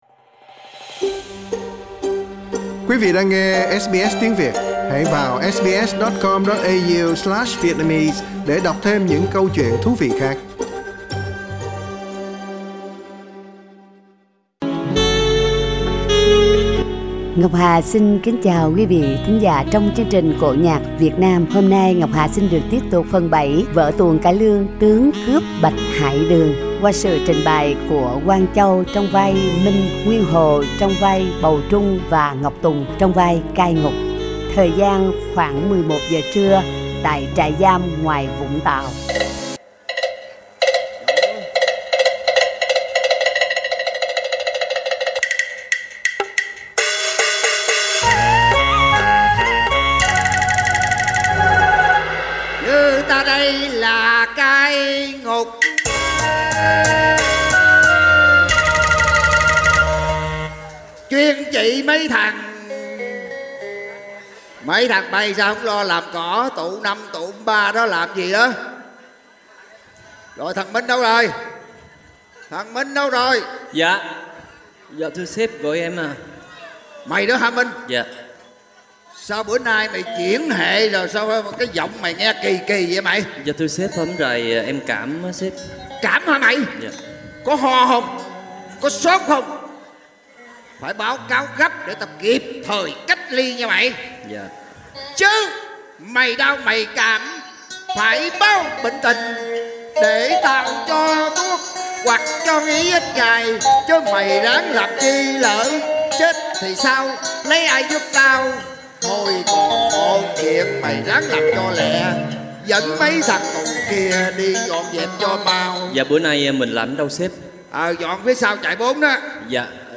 Xin mời quý vị cùng thưởng thức tiếp vở Cải Lương Tướng cướp Bạch Hải Đường do ACE Nghệ sĩ Úc Châu trình diễn tại Sydney.